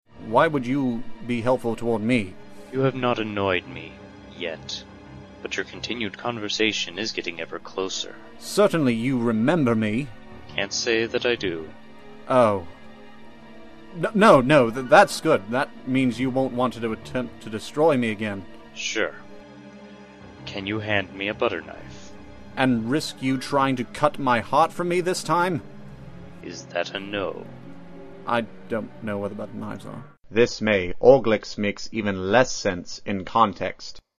We are an Organization-centric audio drama group who love producing crackish comedies for general consumption by other KH fans. This May, we are going to start releasing our work on a wide scale and just to whet your pallet, we have a few samples in the form of these swanky commercials.